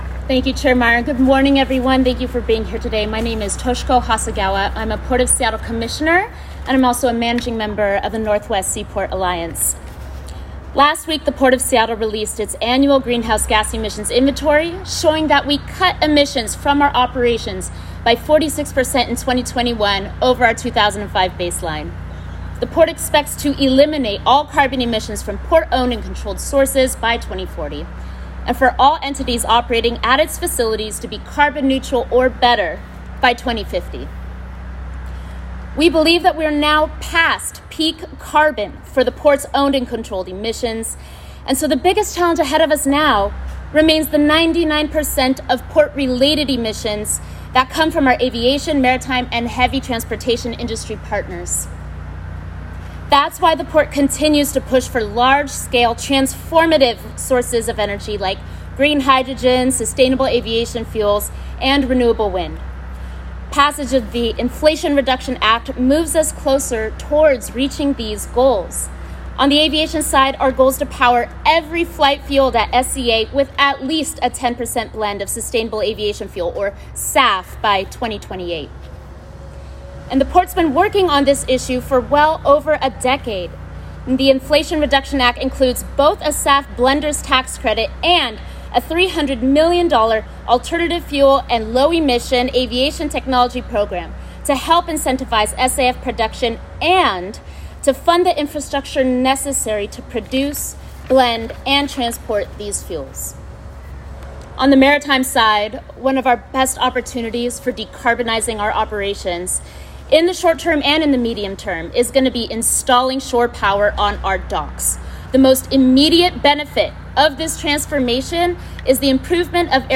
***PHOTOS OF THE PRESS CONFERENCE HERE *** ***AUDIO OF THE PRESS CONFERENCE HERE *** (Seattle, WA) – Today in Seattle, U.S. Senator Patty Murray (D-WA) hosted a press conference highlighting the landmark climate action investments included in the Inflation Reduction Act she helped pass.
Senator Murray held the press conference at Hinoki, a residential building constructed and leased by the Seattle Housing Authority, that includes significant investments in energy saving measures that the Inflation Reduction Act will make more affordable and accessible across Washington state.
8.24-Seattle-Climate-Press-Conference.m4a